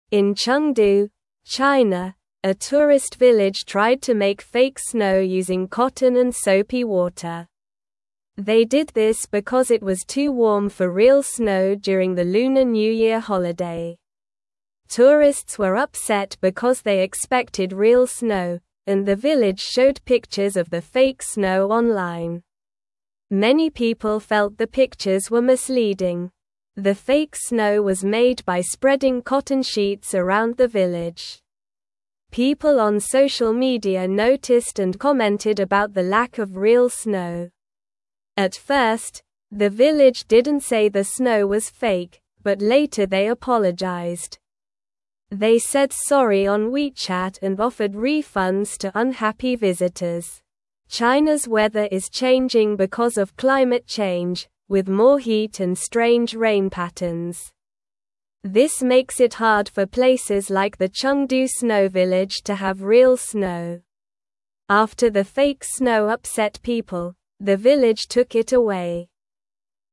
Slow
English-Newsroom-Lower-Intermediate-SLOW-Reading-Village-Makes-Fake-Snow-for-Lunar-New-Year-Fun.mp3